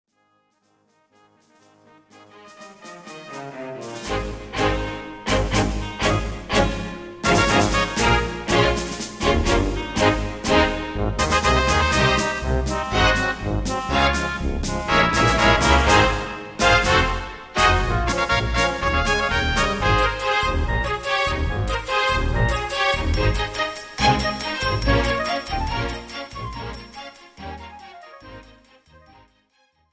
This is an instrumental backing track cover.
• Key – G# / A♭
• Without Backing Vocals
• No Fade